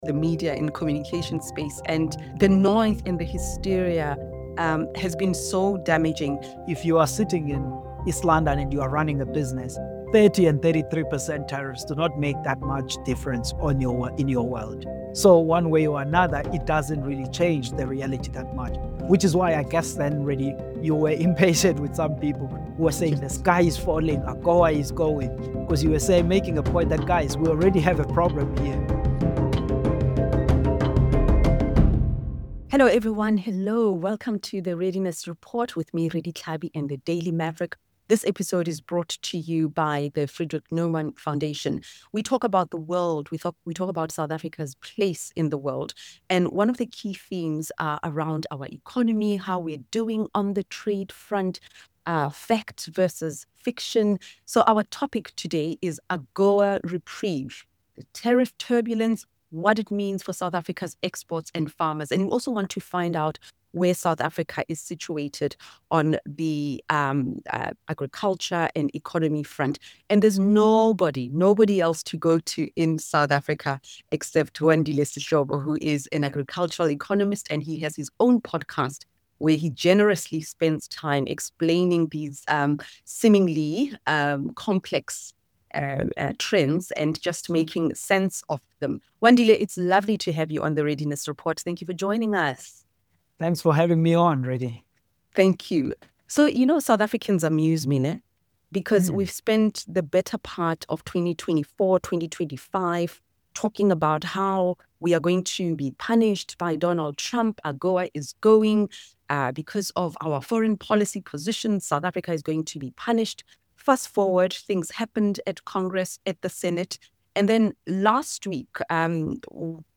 Hosted by: Redi Tlhabi Guest: Wandile Sihlobo